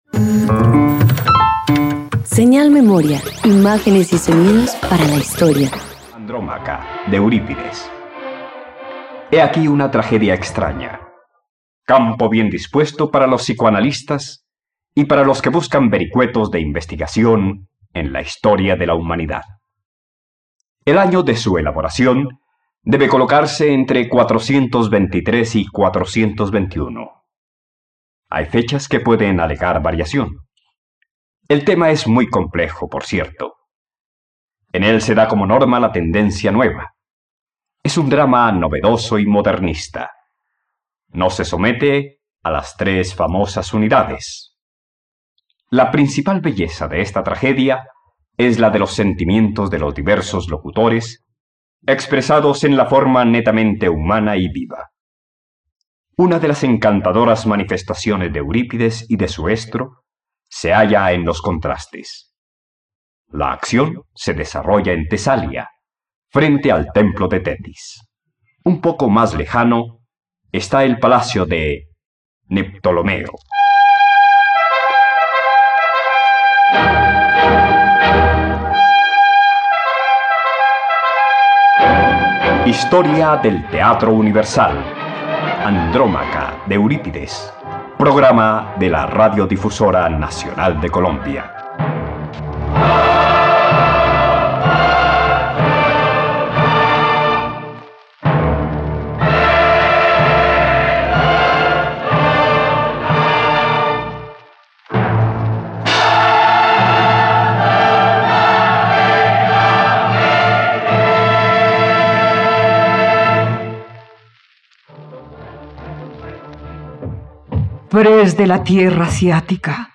Andrómaca - Radioteatro dominical | RTVCPlay
..Radioteatro. Escucha la adaptación radiofónica de “Andrómaca” del poeta girego Eurípides por la plataforma de streaming RTVCPlay.